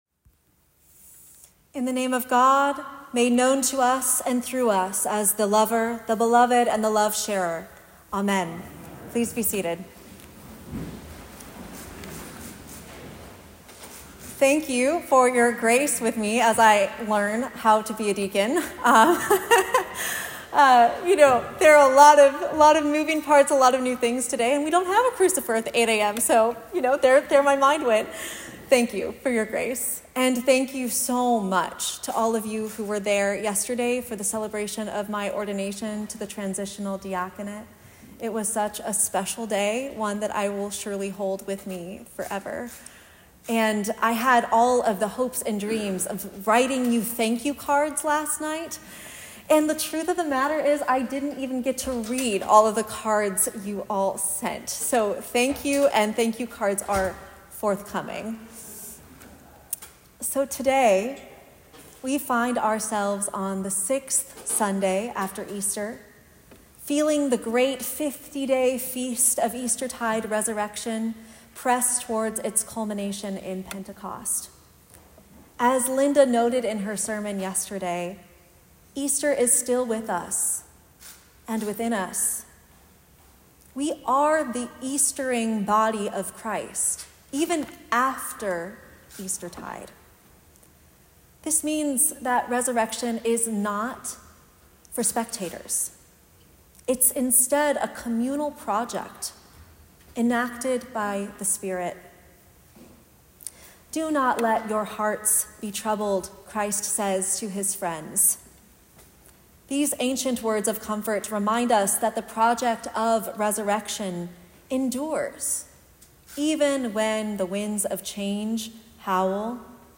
Homily-for-the-6th-Sunday-after-Easter_St.-Martins-Episcopal-Church-2025.m4a